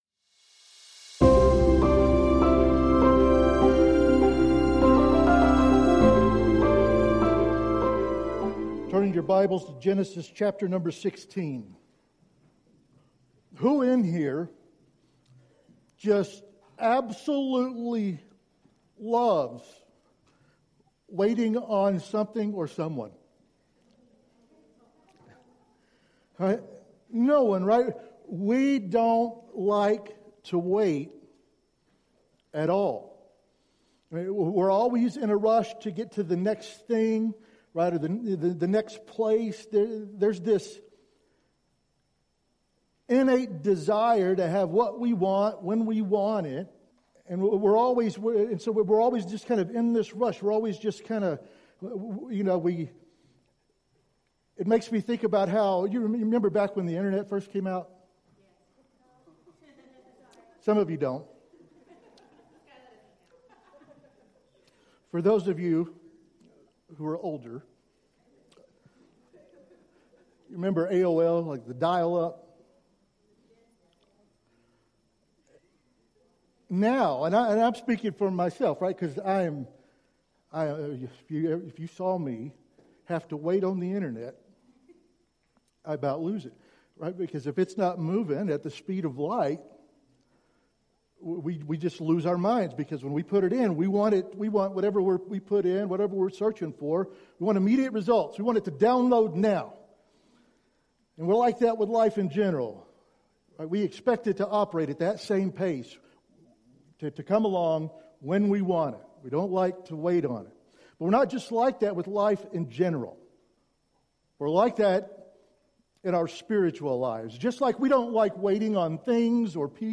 Sermons | Big Horn Baptist Church